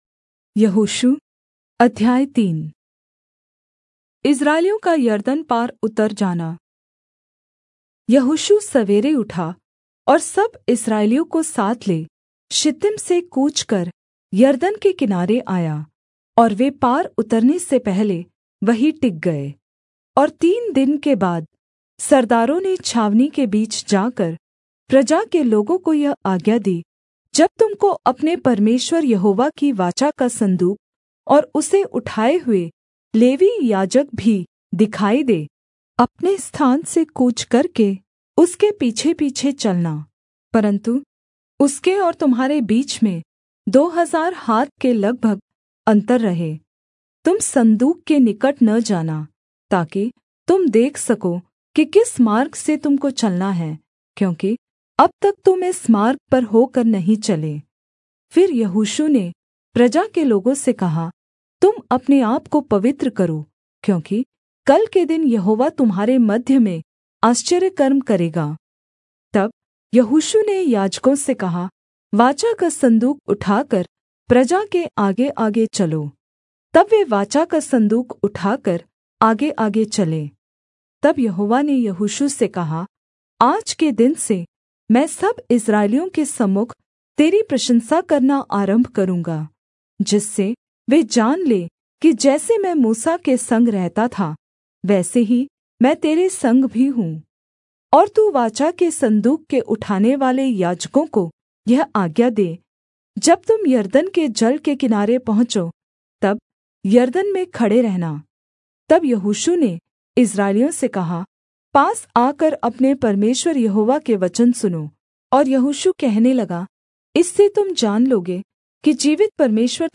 Hindi Audio Bible - Joshua 5 in Irvhi bible version